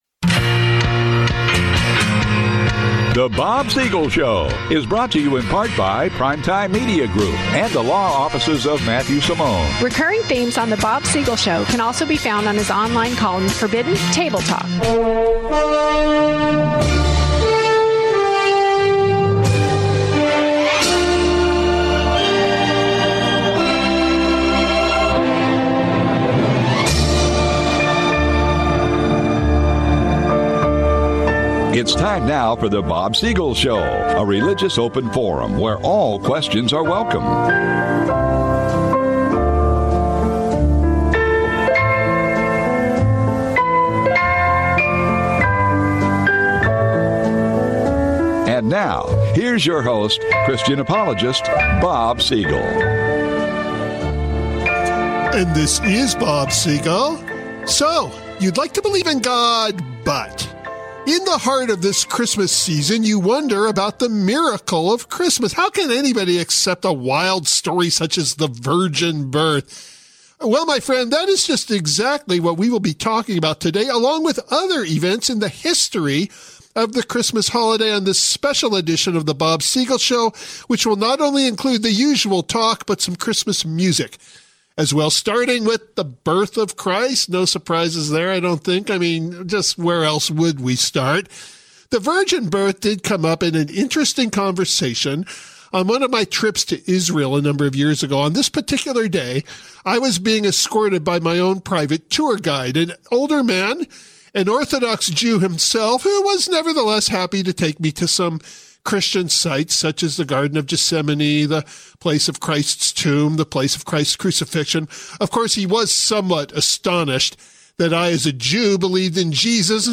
Repeat broadcast
In this special program, a combination of talk and music